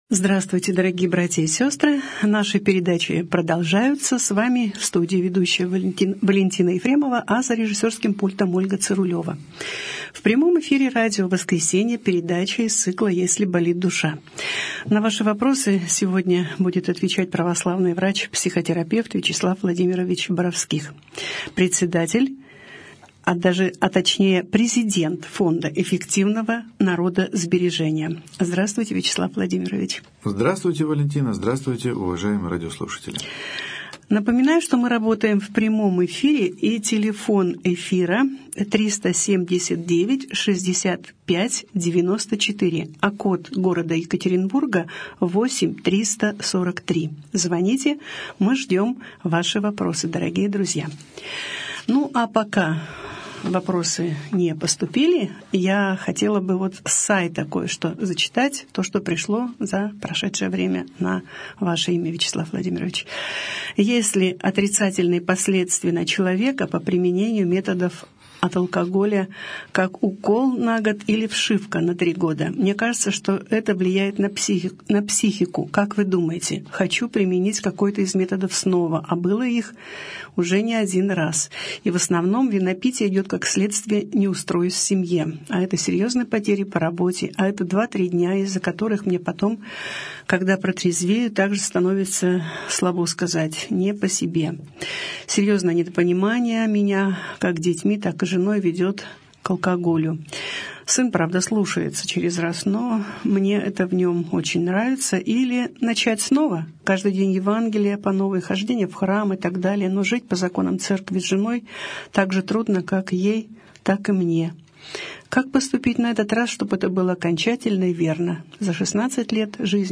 О душевном здоровье. Беседа с психологом | Православное радио «Воскресение»